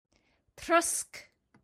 Each week, SAY IT IN IRISH features an Irish or Hiberno-English word or phrase, exploring its meaning, history and origins – with an audio recording by a native Irish speaker from Cork so you can hear how it’s pronounced.
Trosc – pronounced roughly trusk